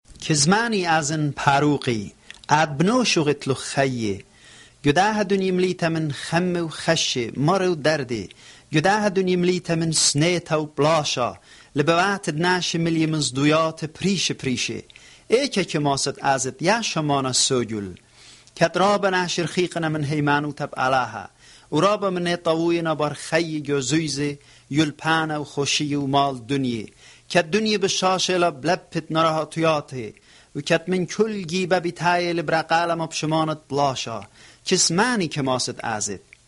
(Beautifully clear recording, by the way.)